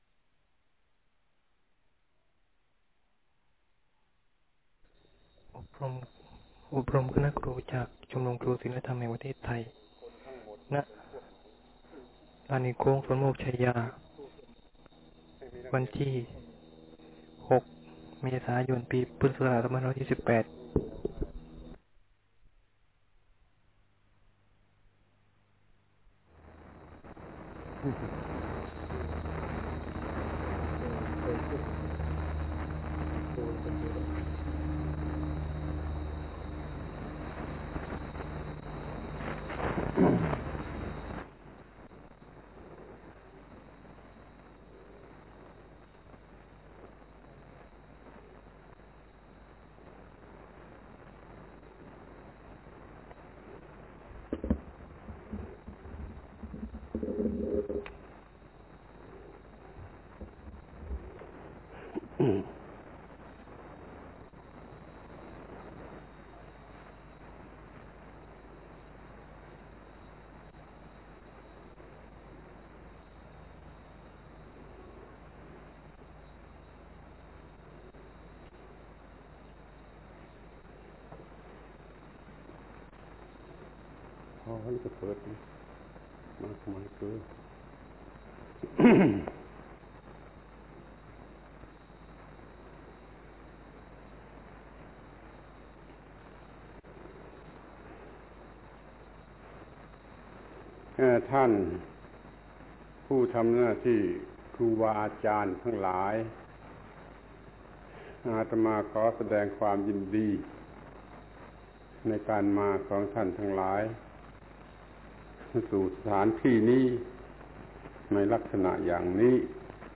อบรมคณะครูจากชมรมครูศีลธรรมแห่งประเทศไทย ณ ลานหินโค้งสวนโมกข์ไชยา วันที่ 6 เมษายน ปีพุทธศักราช 2548 ท่านผู้ทำหน้าที่ครูบาอาจารย์ทั้งหลาย อาตมาขอแสดงความยินดีใน ...